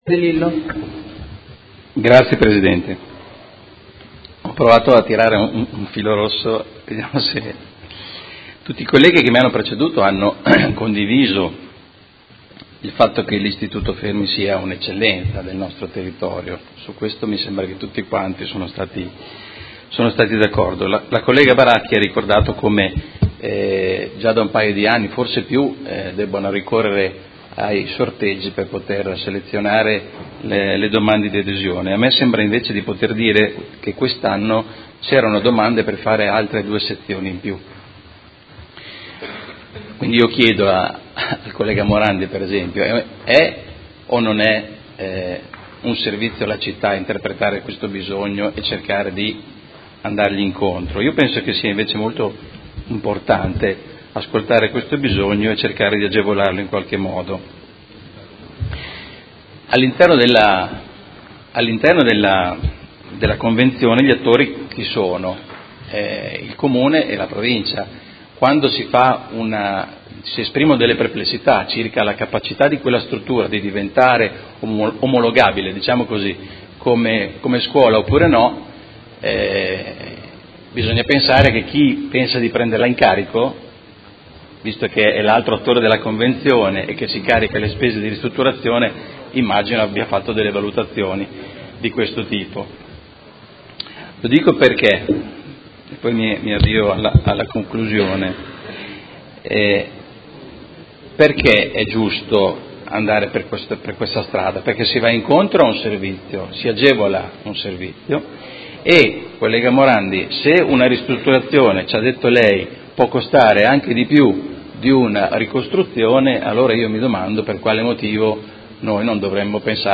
Seduta del 31/05/2018 Dibattito. Delibera. Convenzione tra Comune di Modena e Provincia di Modena per la concessione in uso gratuito alla Provincia di Modena dell’Immobile “Istituto Ramazzini” posto in Via Luosi n. 130 a Modena